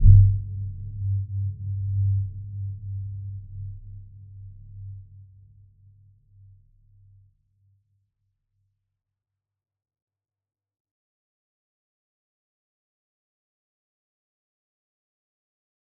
Dark-Soft-Impact-G2-f.wav